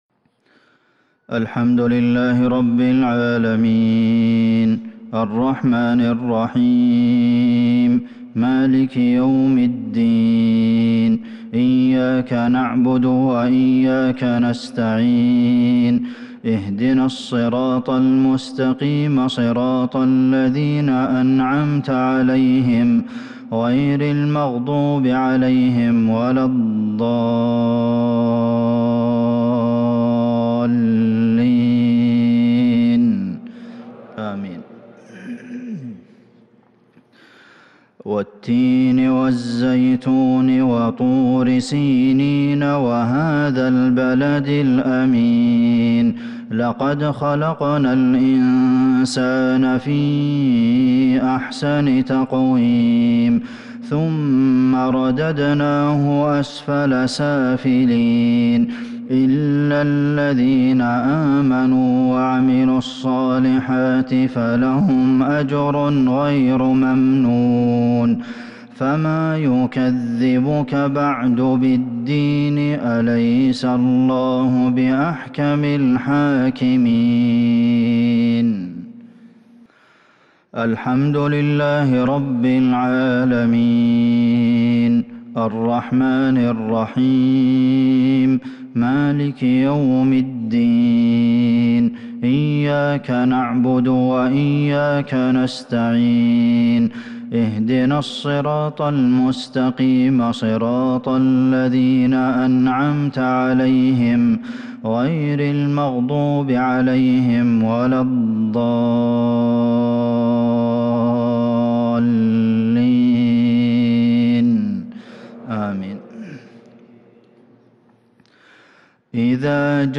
صلاة المغرب للشيخ عبدالمحسن القاسم 26 جمادي الأول 1442 هـ
تِلَاوَات الْحَرَمَيْن .